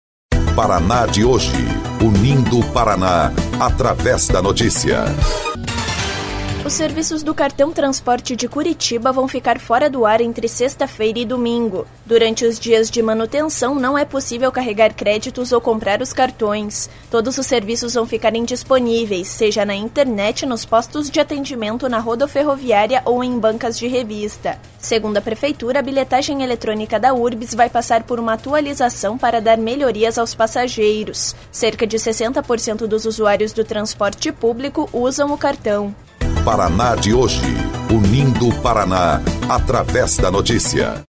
BOLETIM – Serviços do cartão-transporte de Curitiba ficarão fora do ar para atualização